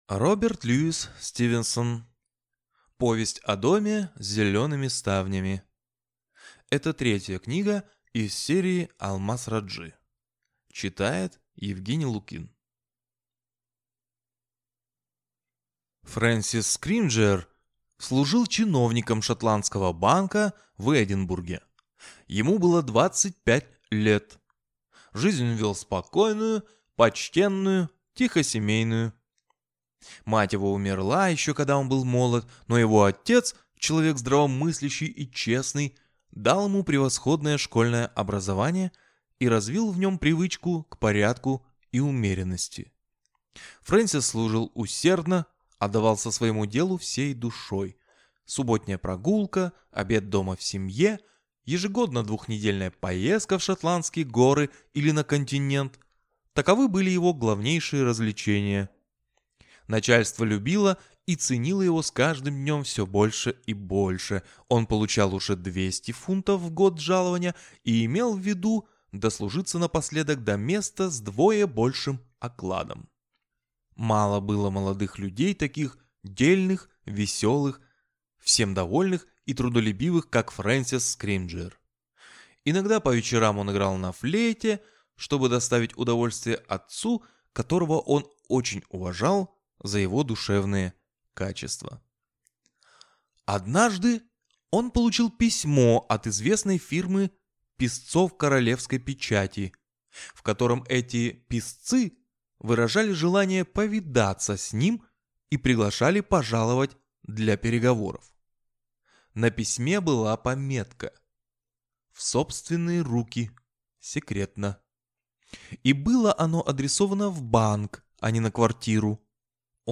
Аудиокнига Повесть о доме с зелеными ставнями | Библиотека аудиокниг